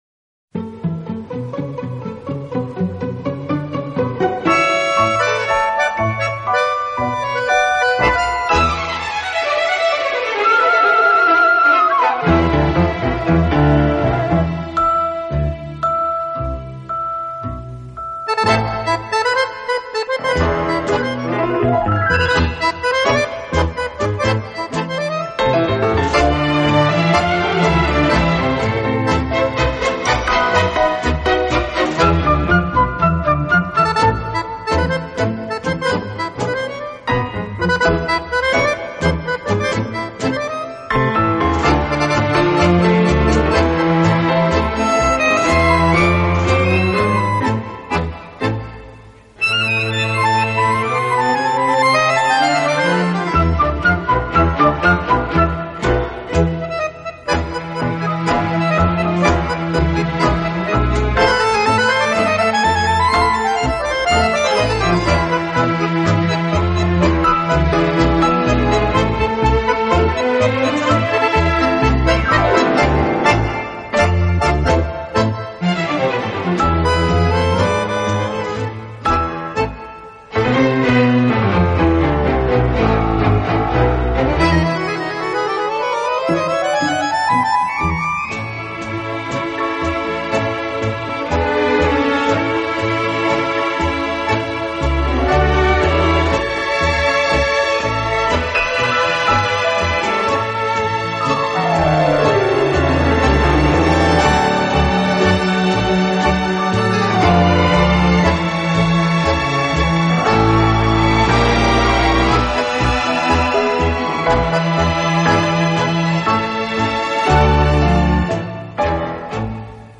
在跳探戈的时候节奏的偶尔停顿让舞者更加兴奋。
曲子开头通常伴有许多手风琴，这使得音乐在某种程度上稍具古风。